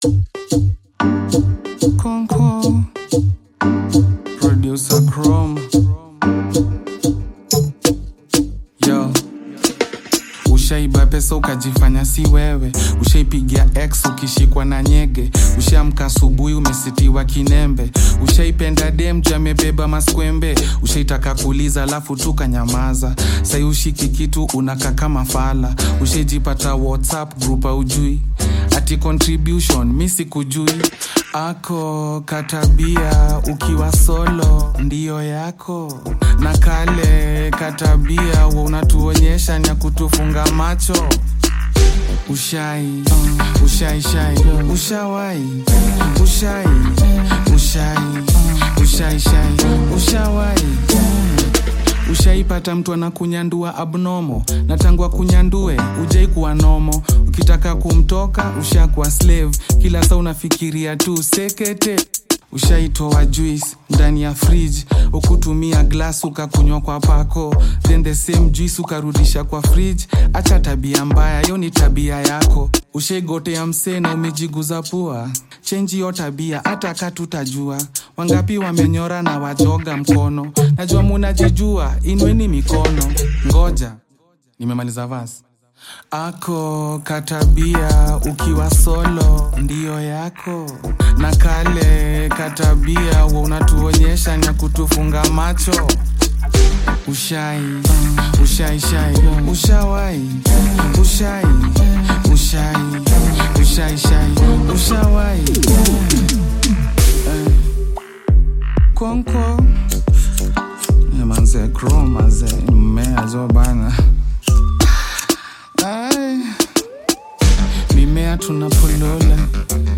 Kenyan music track
Bongo Flava